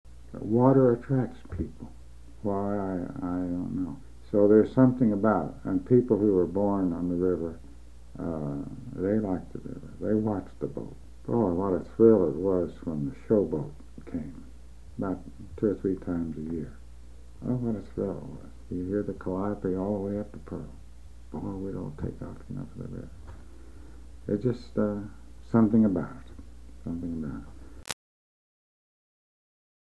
HTR Oral History